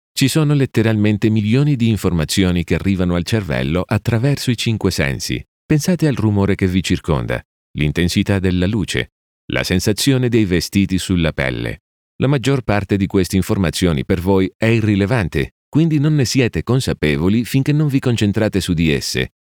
Young Adult, Adult, Mature Adult
Has Own Studio
I can deliver a wide range of dynamic nuances and styles, whether you need a deep engaging tone for a movie trailer; a unique voice for a TV Commercial; a warm and natural read for a documentary; or a friendly conversational feel for a corporate video.
COMMERCIAL 💸